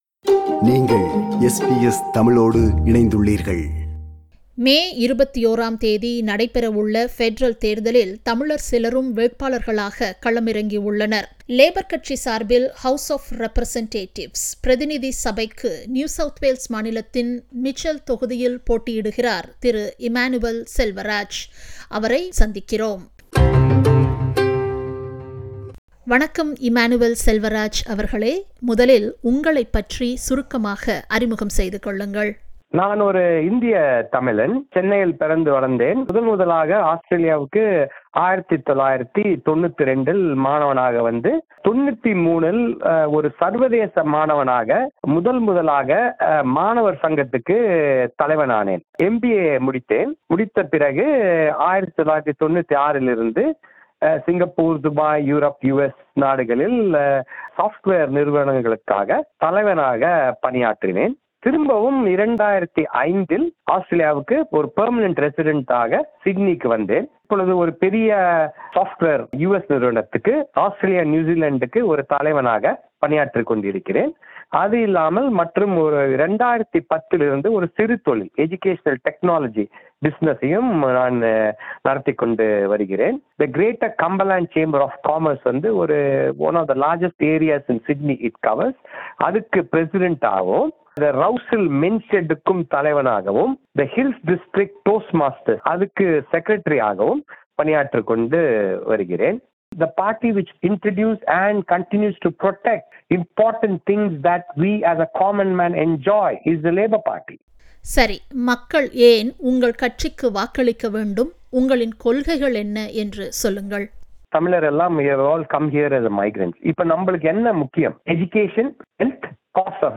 Federal election 2022 : Interview